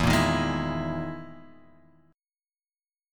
EM7b5 chord {0 1 1 1 x 0} chord